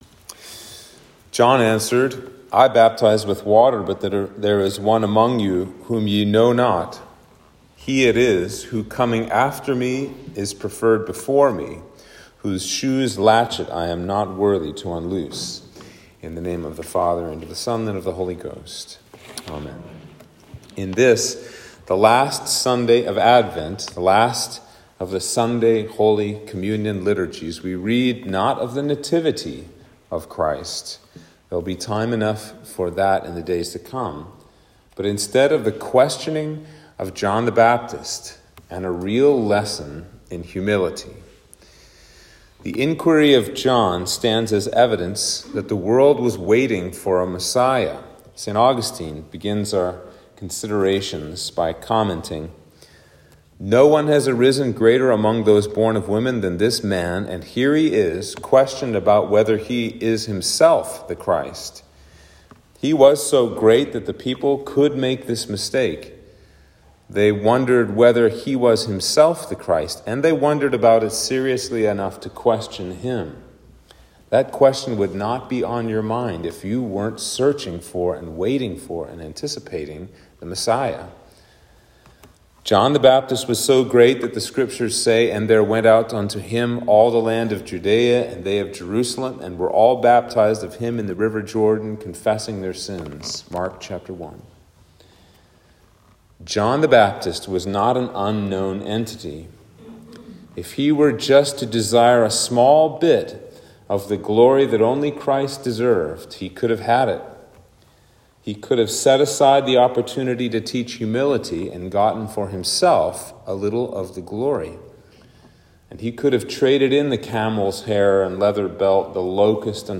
Sermon for Advent 4